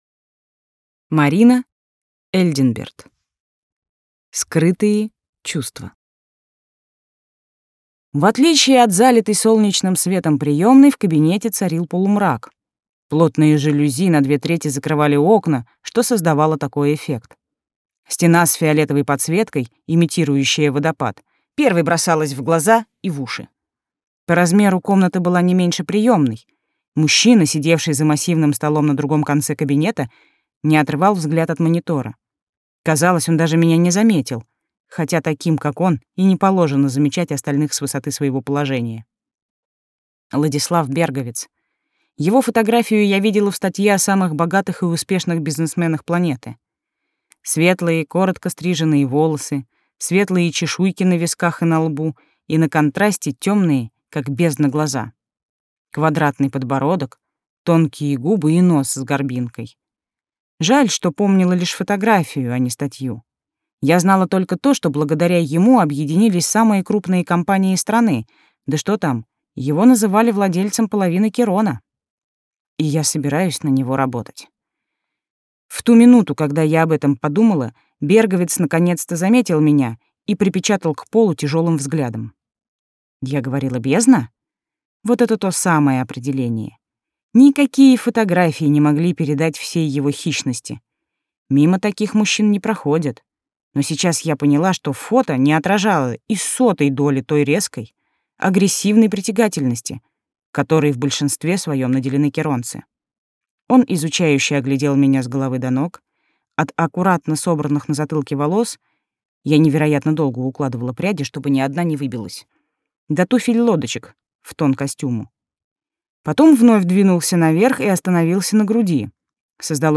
Аудиокнига Скрытые чувства - купить, скачать и слушать онлайн | КнигоПоиск